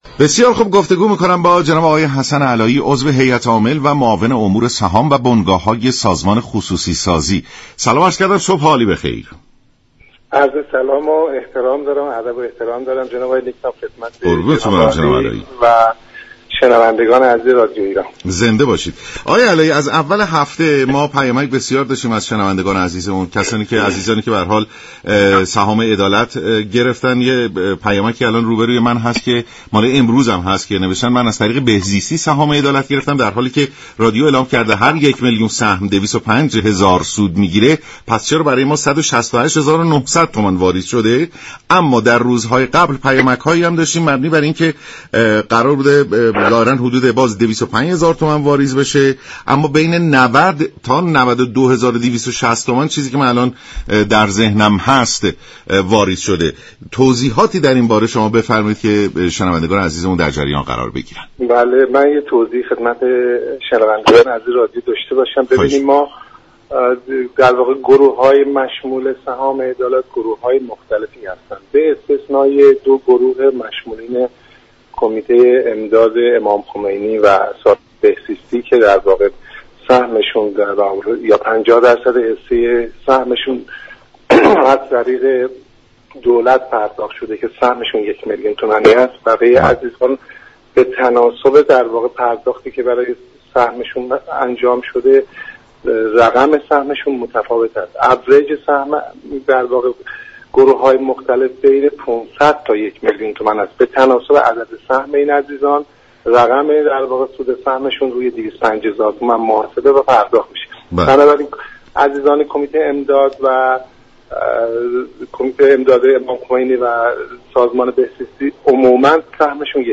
به گزارش شبكه رادیویی ایران، حسن علایی عضو هیات عامل و معاون امور سهام و بنگاه های سازمان خصوصی سازی در برنامه «سلام صبح بخیر» درباره جزئیات سهام عدالت و ابهامات آن گفت: مشمولان دریافت سهام عدالت گروه های زیادی را شامل می شوند و به جز افراد تحت پوشش كمیته امداد امام خمینی (ه) و سازمان بهزیستی كه سهم شان معادل یك میلیون تومان است، دیگر دریافت كنندگان رقمشان با یكدیگر متفاوت است.